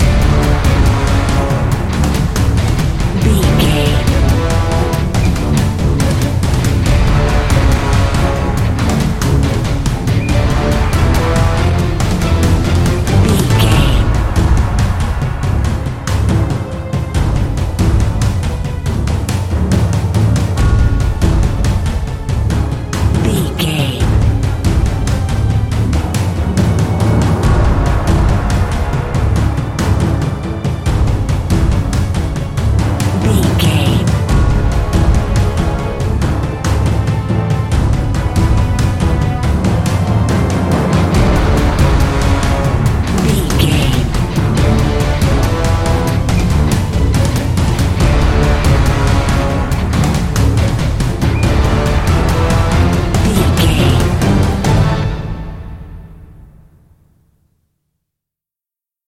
Epic / Action
Fast paced
In-crescendo
Aeolian/Minor
strings
horns
percussion
electric guitar
orchestral hybrid
dubstep
aggressive
energetic
intense
synth effects
wobbles
driving drum beat